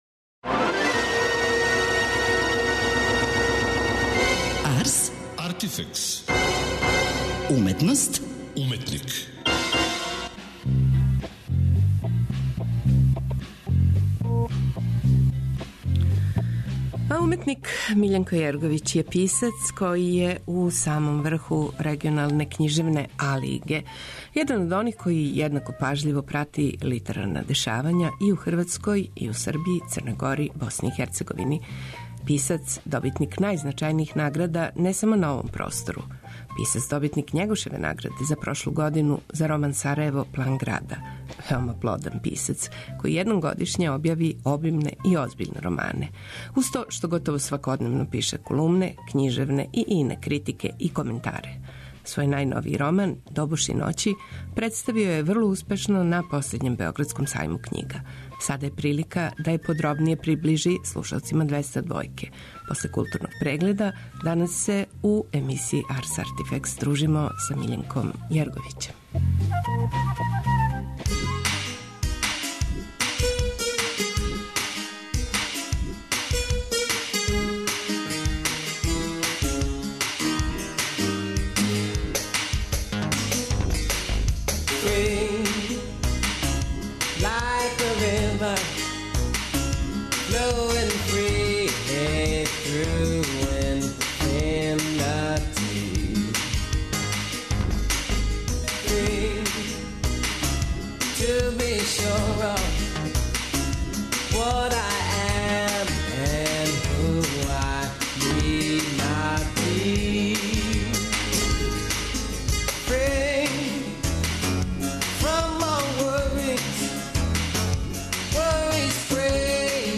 Тада смо и најавили дужи разговор са аутором, књижевником који представља сам врх регионалне књижевности.